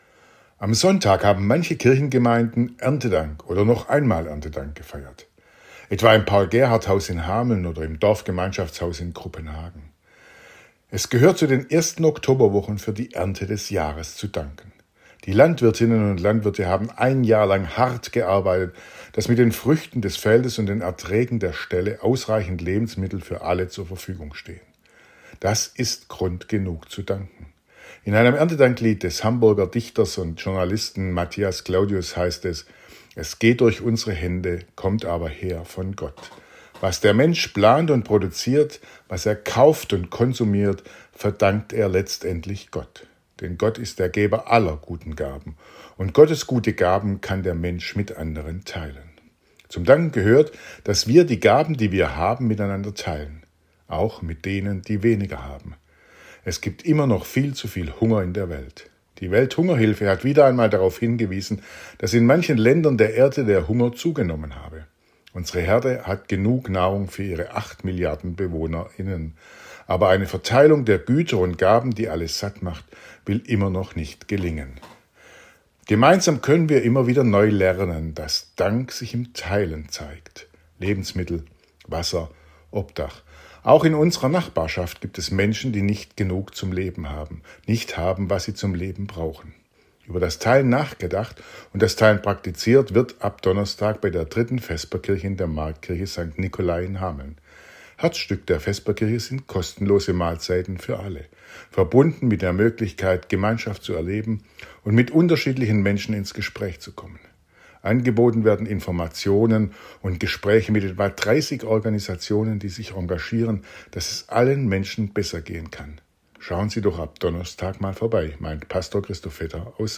Radioandacht vom 13. Oktober